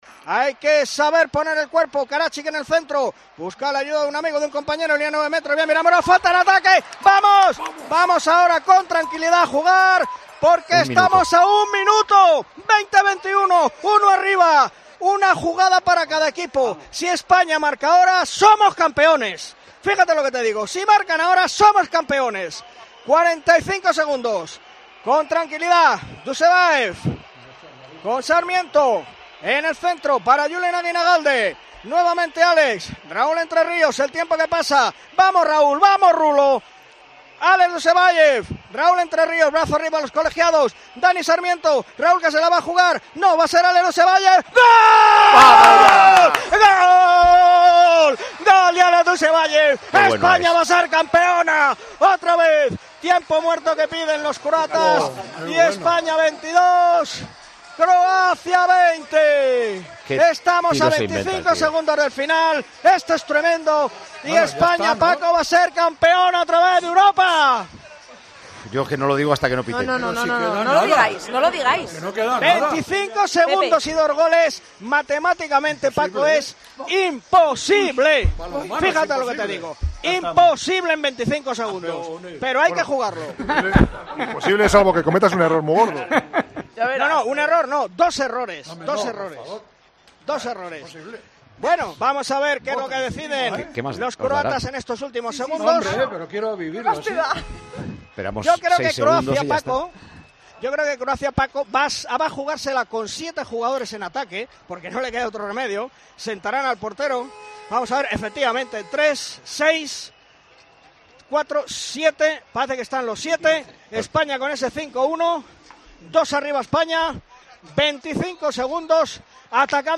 desde Suecia, narró la victoria de la selección española de balonmano en el Europeo.